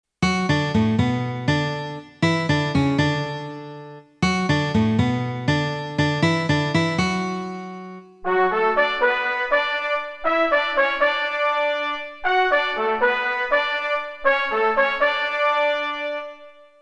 Эстрадно-(симфо?)-оркестровка №1)